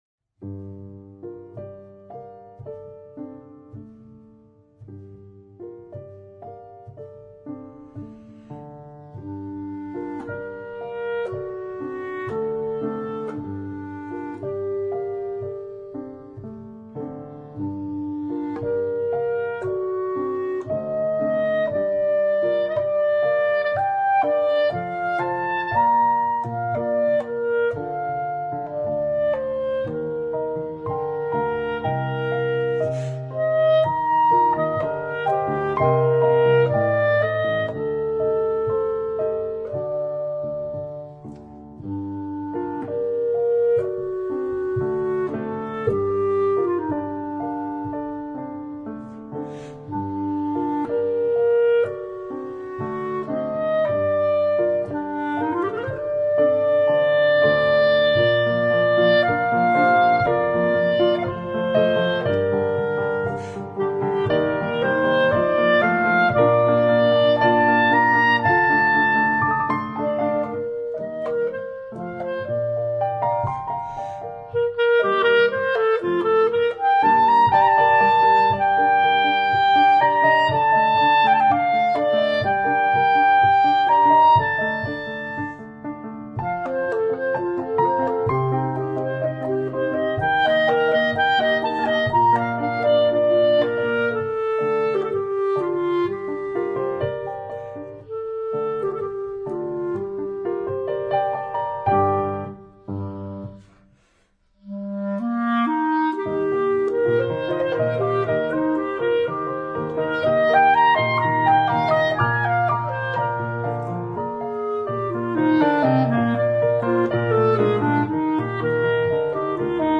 for clarinet and piano